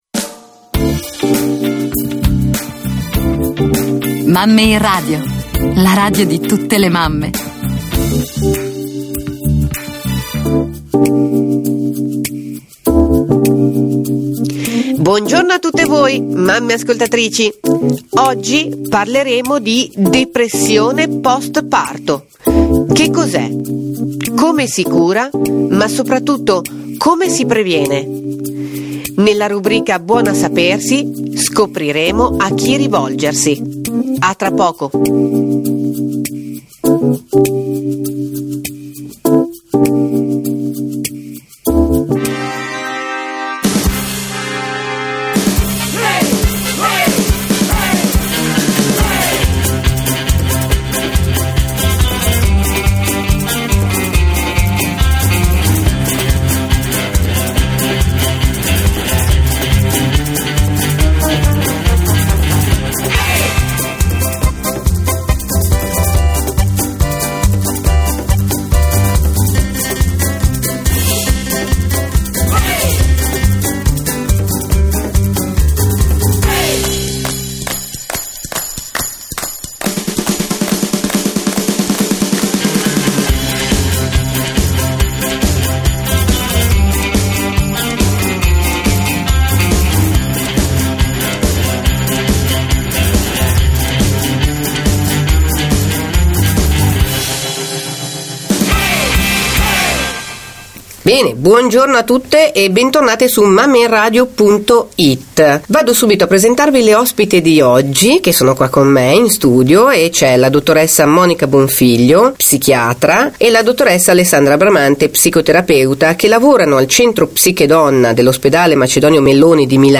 Nel corso della diretta radio si è parlato di differenza tra baby blues e depressione post parto, di come è possibile riconoscere i vari stadi della depressione e uscire da questo periodo di difficoltà.